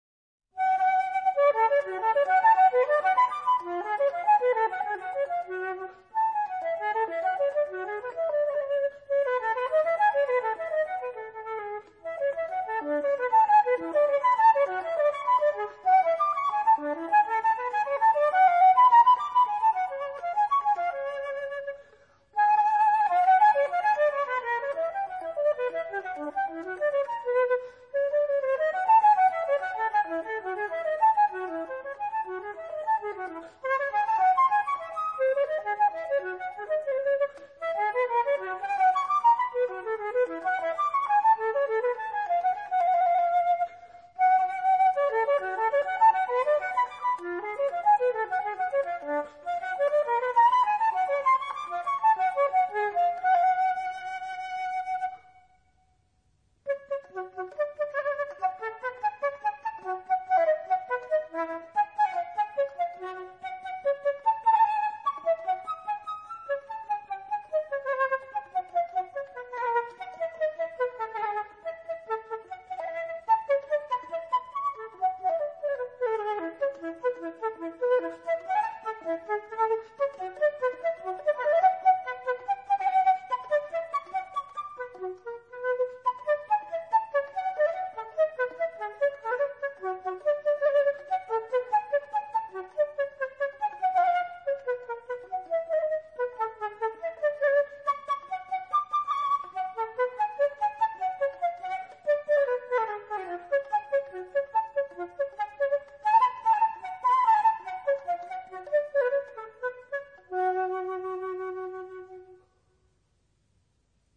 長笛作品